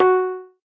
pling.ogg